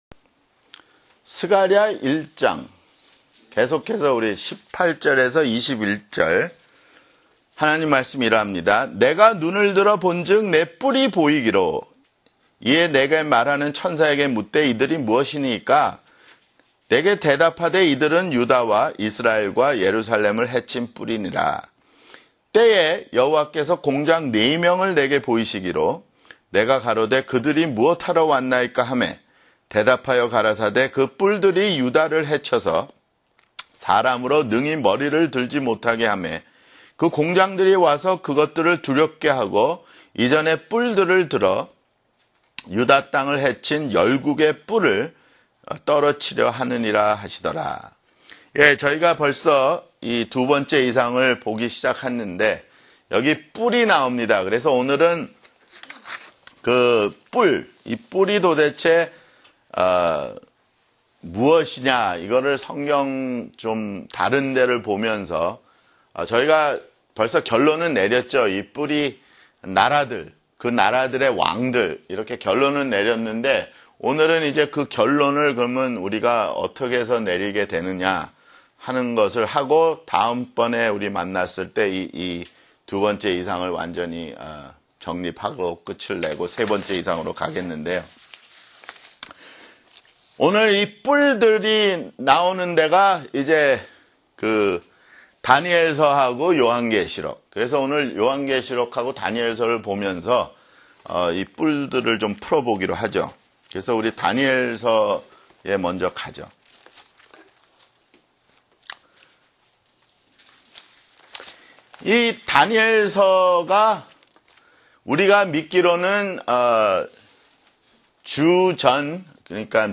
[성경공부] 스가랴 (13)